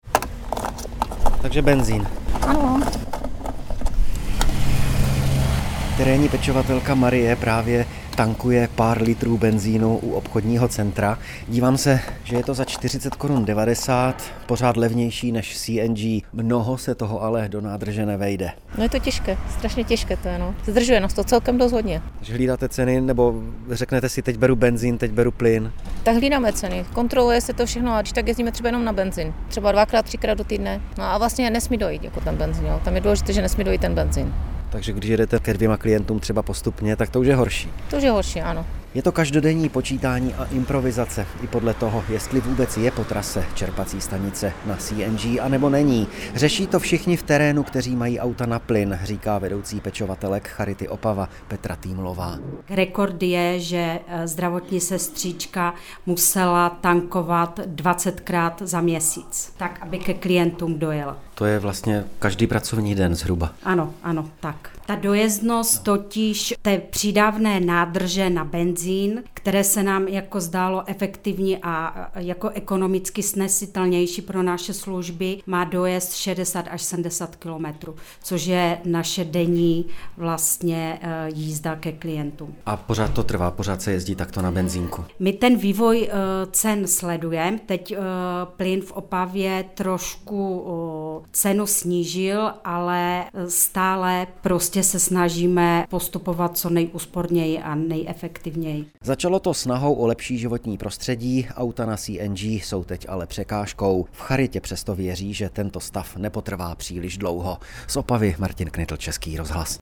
Reportáž Českého rozhlasu - provoz CNG automobilů se Charitě Opava prodražuje